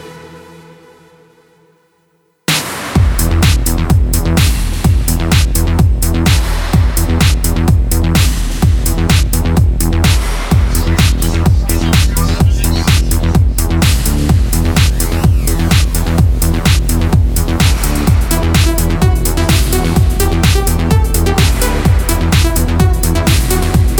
no Backing Vocals Dance 3:12 Buy £1.50